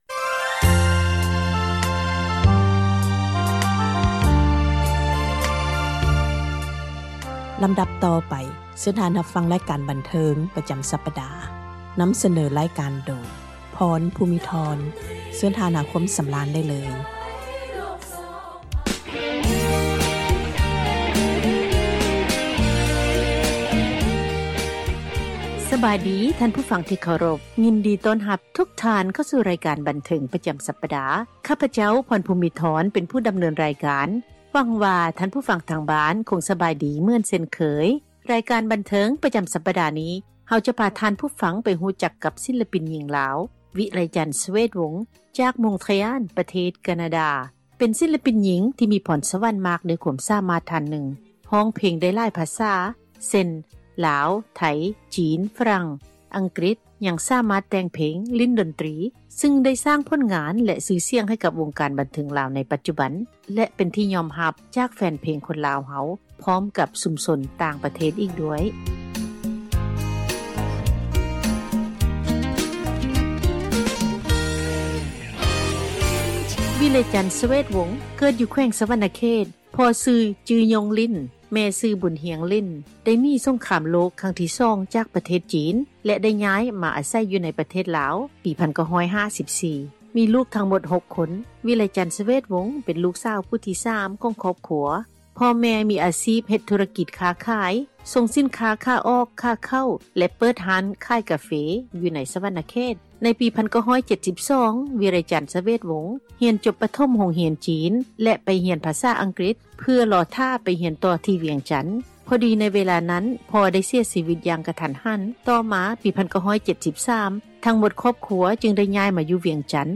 ສັມພາດ ສິລປິນຍິງ ທີ່ມີຄວາມສາມາດ ໃນການຫລິ້ນກິດຕາຣ໌, ຮ້ອງເພງ ແລະ ແຕ່ງເພງ, ຊຶ່ງປັດຈຸບັນ ຕັ້ງຖິ່ນຖານ ຢູ່ທີ່ມົງເຕຣອາລ, ປະເທດ ການາດາ.